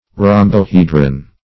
rhombohedron - definition of rhombohedron - synonyms, pronunciation, spelling from Free Dictionary
Rhombohedron \Rhom`bo*he"dron\, n. [NL., fr. Gr.